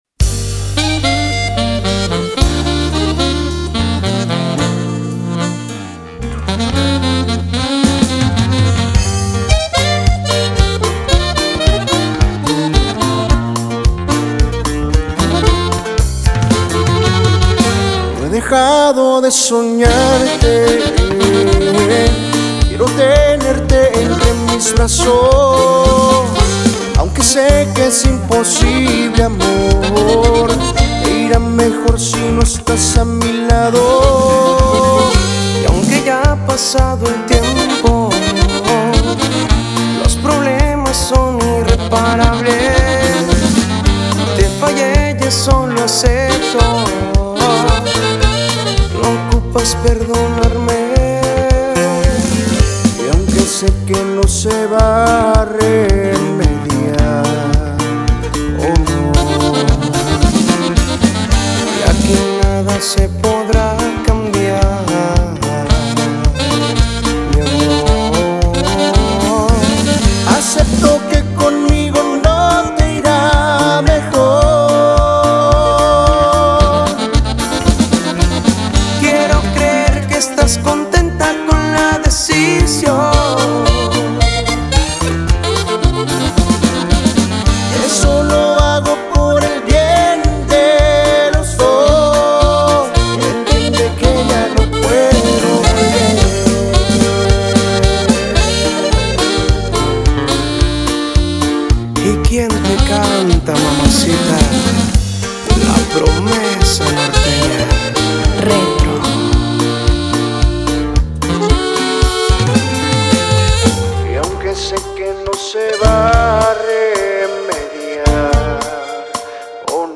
balada norteña
norteño sax